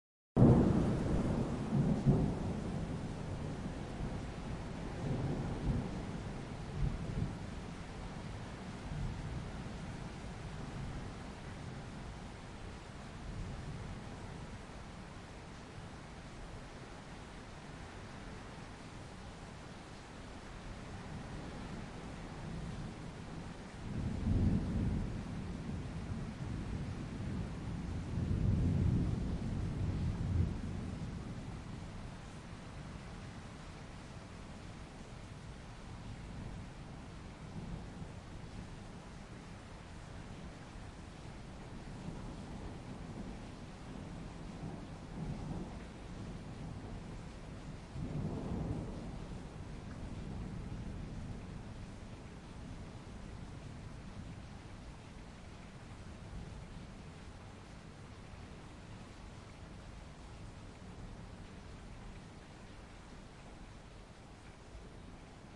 Storm From Indoors Bouton sonore